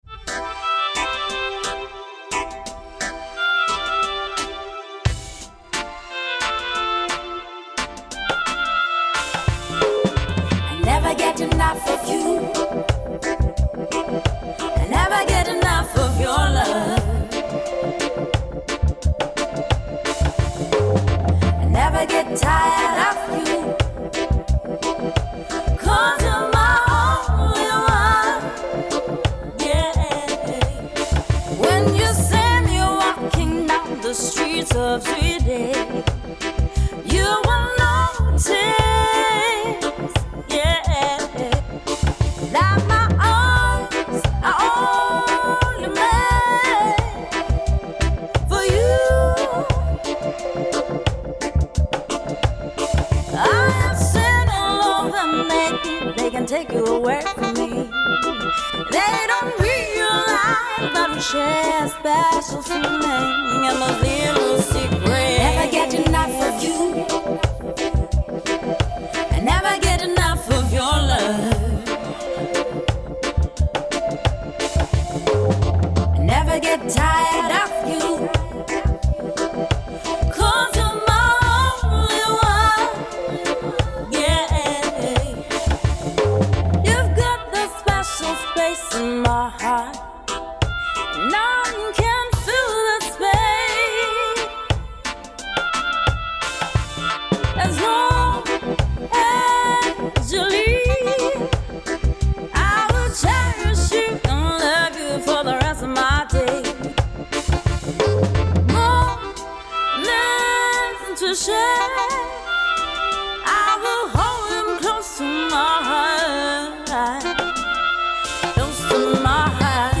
Genre: Reggae Jazz